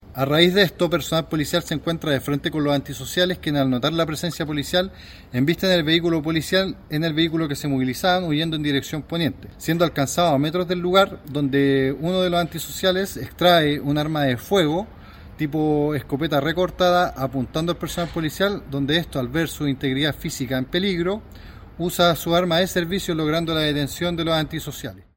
Finalmente, cuatro de ellos fueron detenidos en el mismo lugar y un quinto huyó con una escopeta, apuntando contra los carabineros. Por esto, un cabo segundo debió utilizar su arma de servicio para reducirlo, tal como relató el capitán